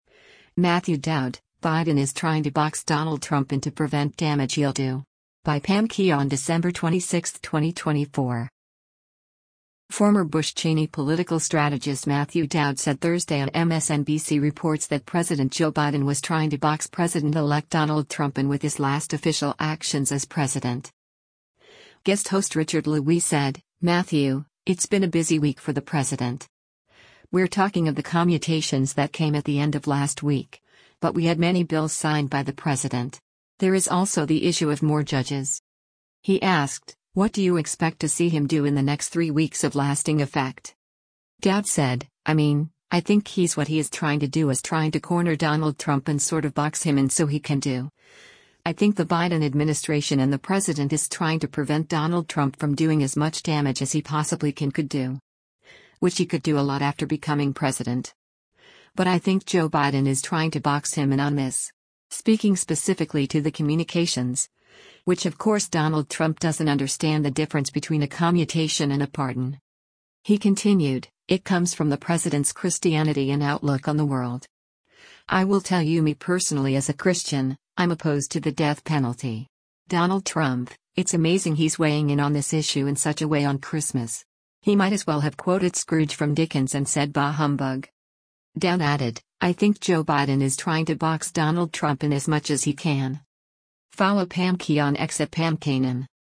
Former Bush-Cheney political strategist Matthew Dowd said Thursday on “MSNBC Reports” that President Joe Biden was trying to box President-elect Donald Trump in with his last official actions as president.